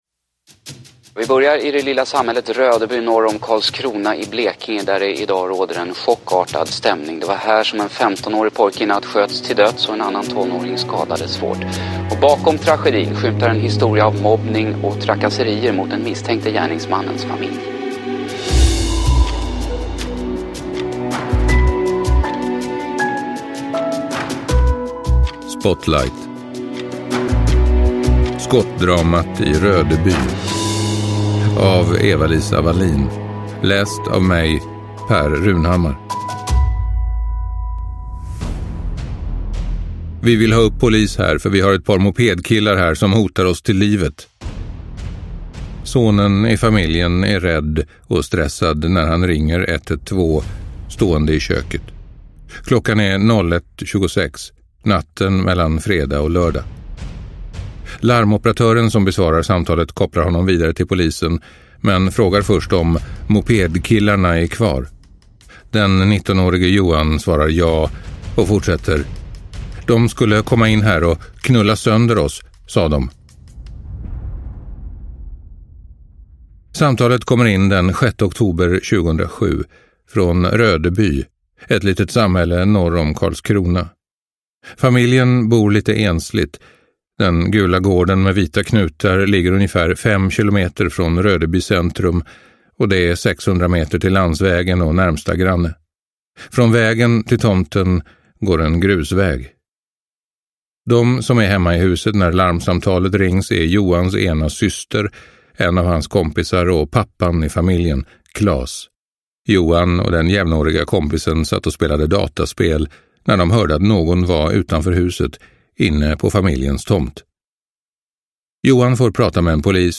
Skottdramat i Rödeby – Ljudbok – Laddas ner